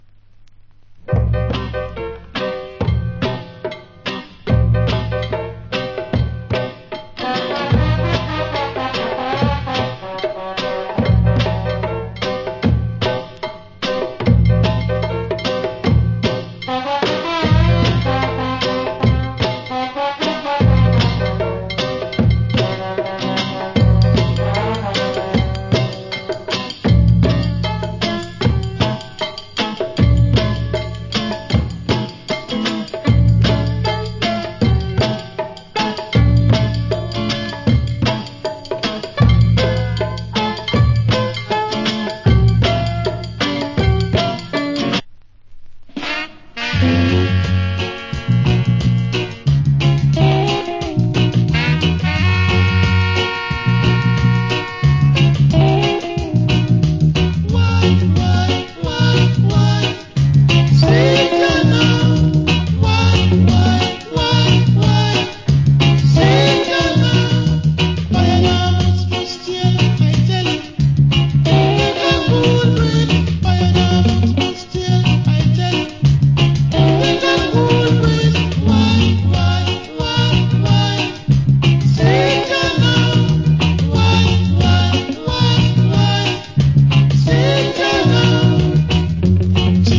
Cool Rock Steady.